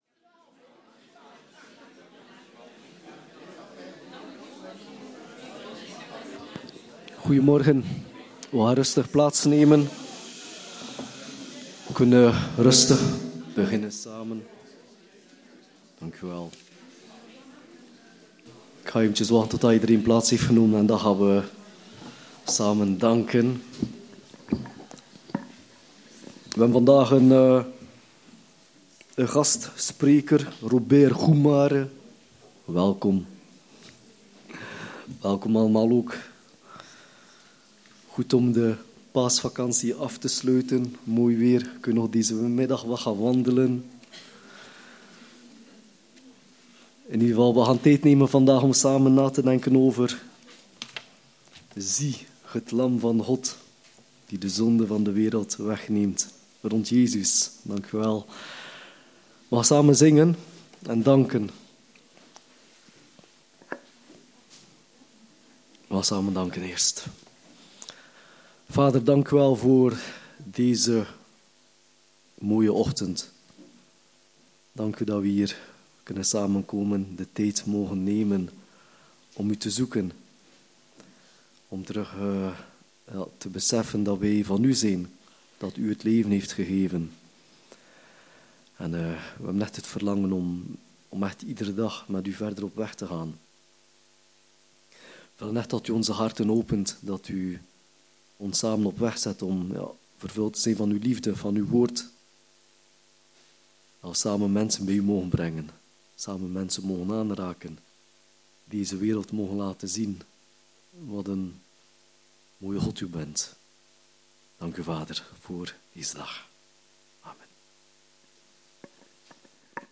Laten wij ons in deze dienst laten aanspreken door het getuigenis van Johannes en ons hart openen voor het bevrijdende perspectief dat het Lam van God ons schenkt.